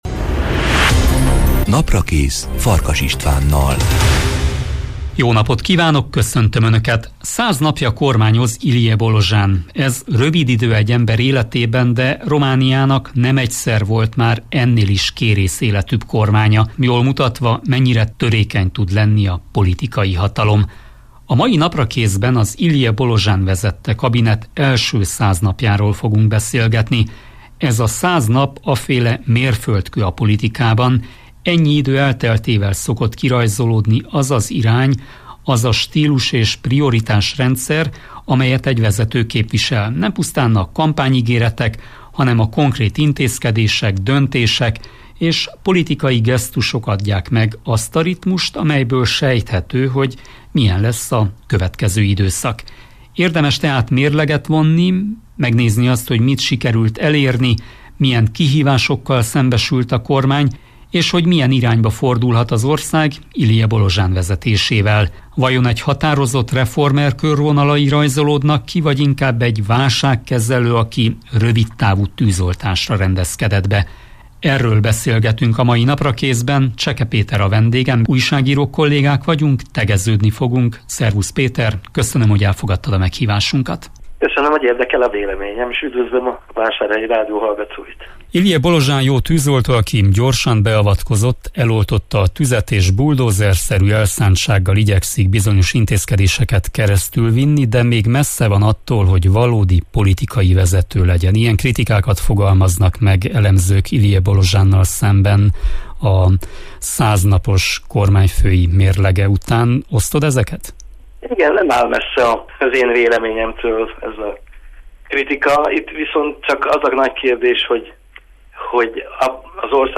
A mai Naprakészben az Ilie Bolojan vezette kabinet első száz napjáról fogunk beszélgetni.